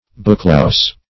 booklouse \book"louse`\ n. (Zool.)